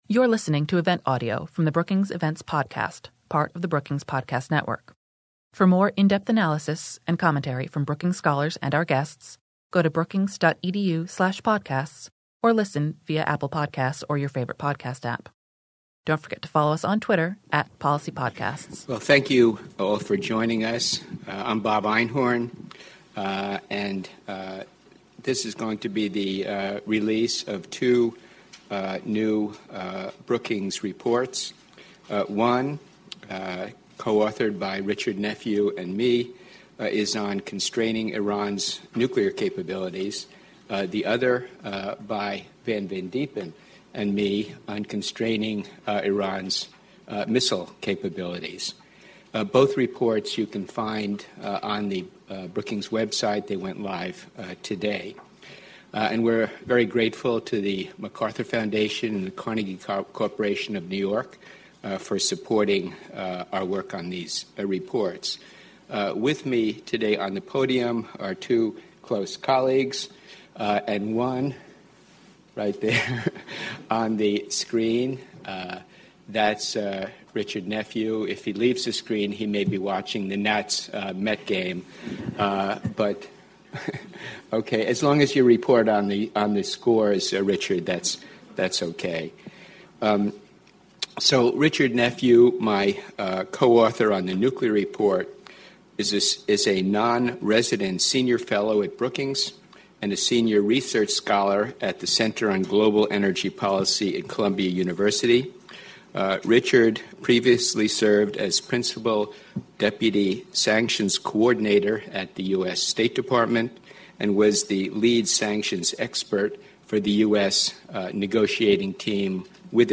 After their discussion, the panelists answered questions from the audience.